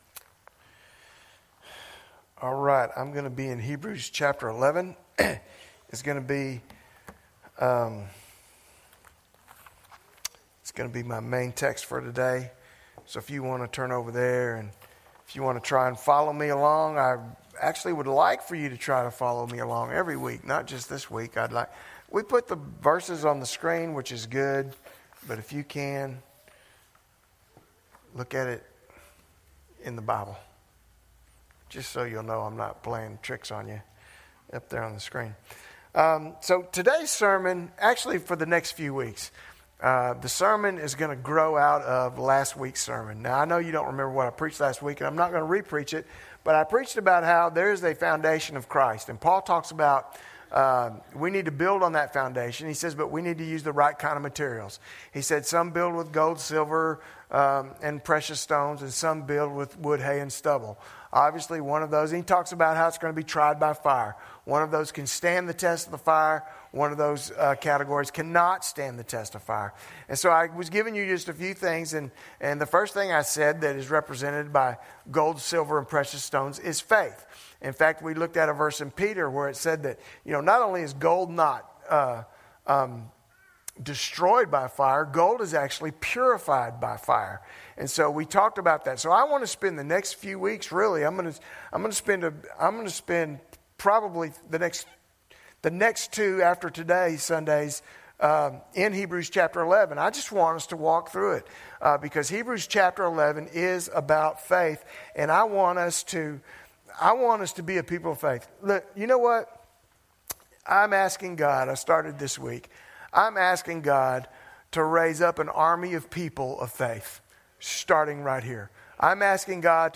Sermons presented at First Baptist Church Bridge City in the 2020 and 2021.